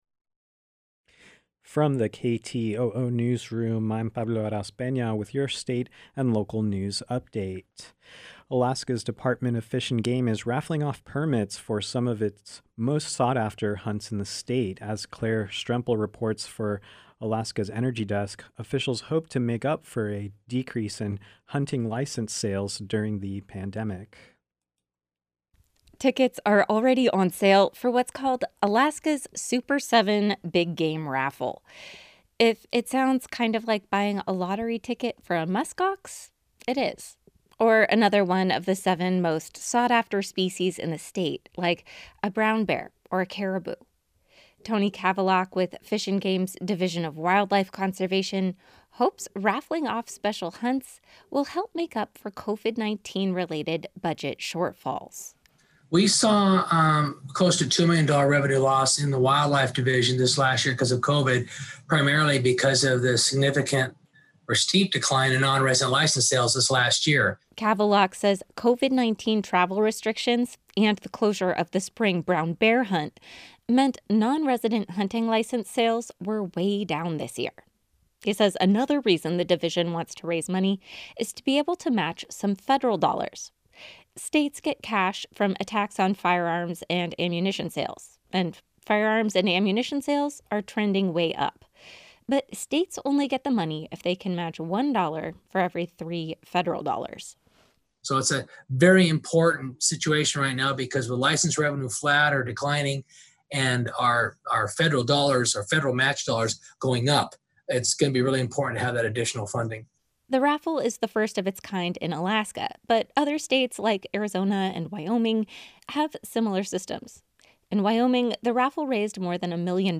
Newscast — Friday, March 12, 2021